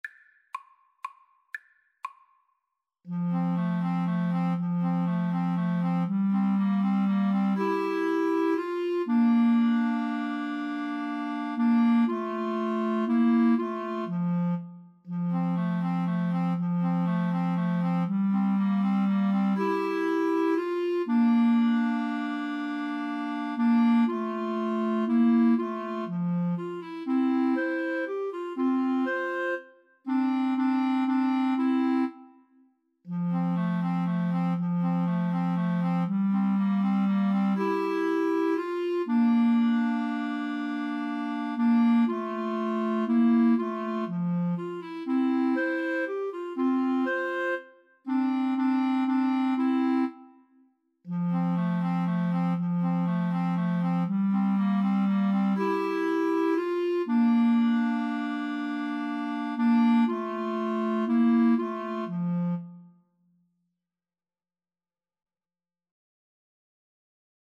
3/4 (View more 3/4 Music)
Clarinet Trio  (View more Easy Clarinet Trio Music)
Classical (View more Classical Clarinet Trio Music)